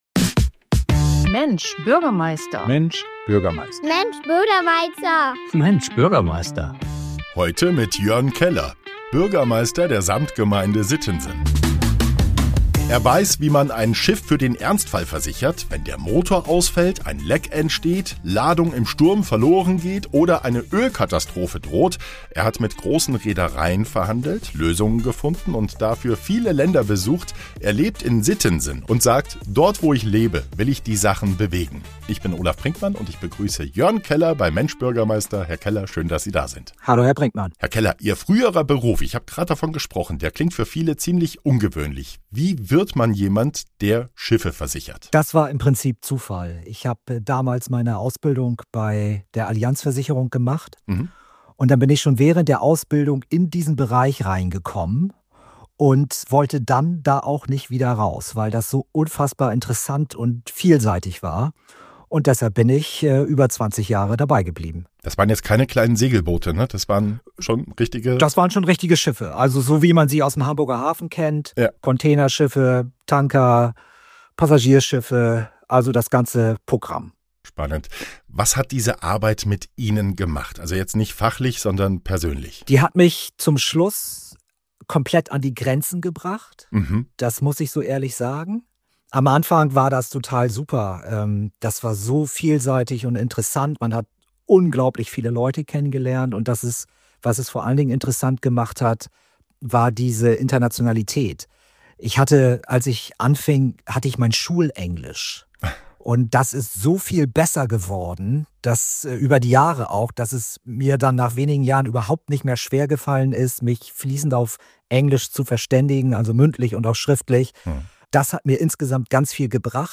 In dieser Folge von „Mensch, Bürgermeister!“ ist Jörn Keller, Bürgermeister der Samtgemeinde Sittensen, zu Gast.